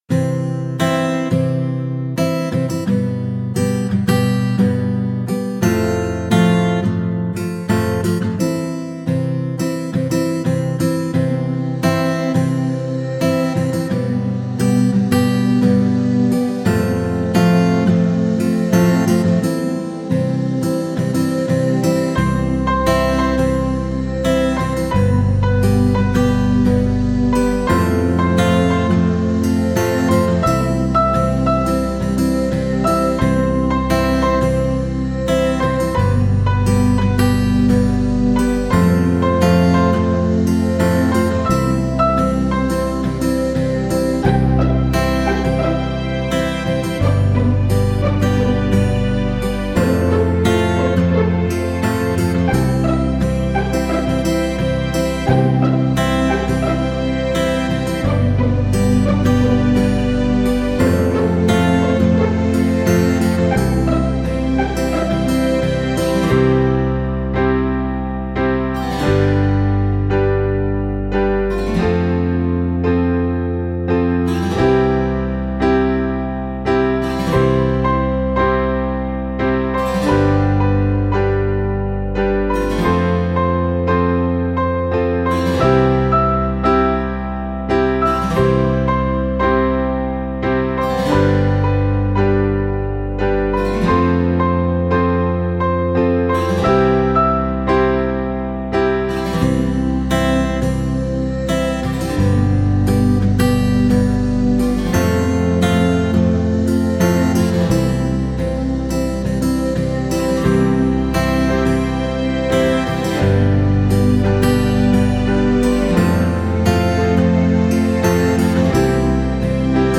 это энергичная композиция в жанре пост-хардкор